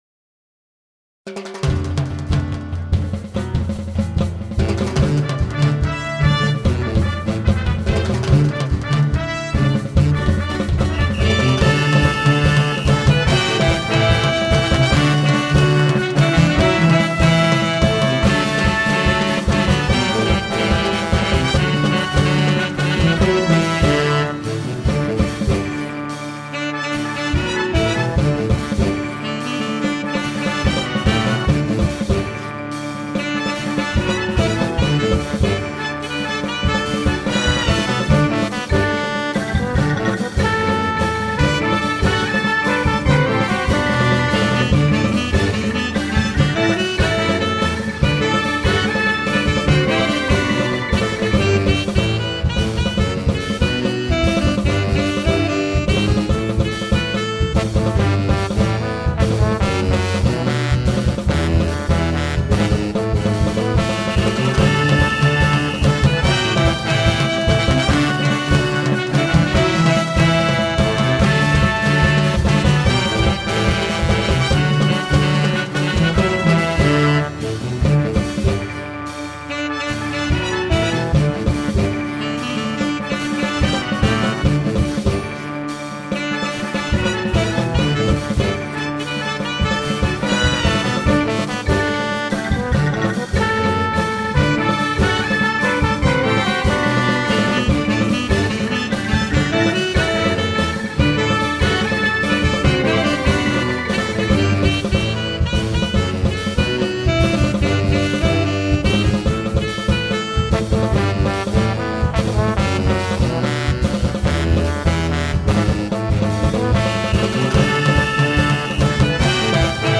Base musicale